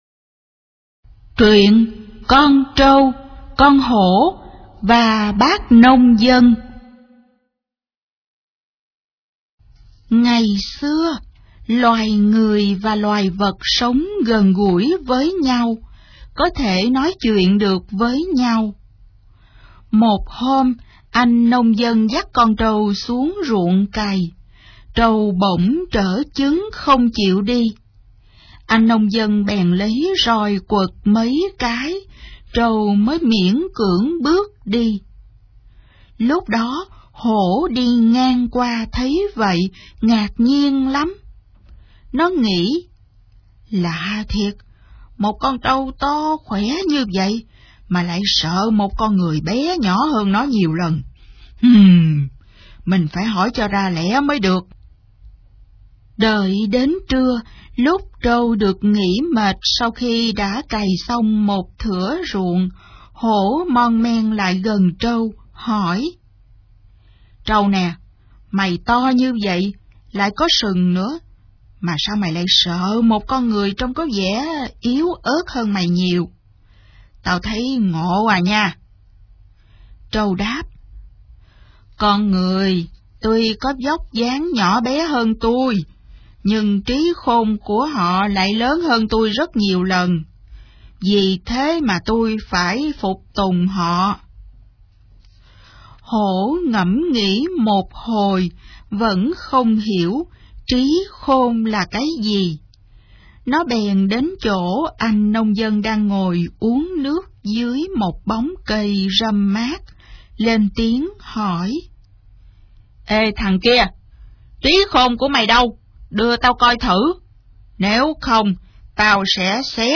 Sách nói | Con Trâu, Con Hổ & Bác Nông Dân